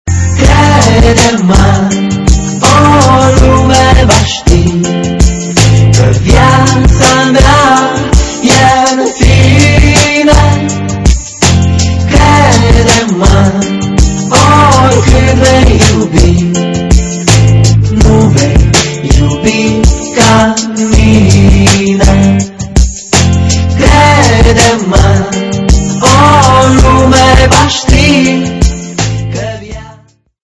英文DJ版